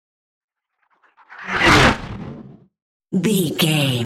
Whoosh fast airy cinematic
Sound Effects
Fast
futuristic
intense
whoosh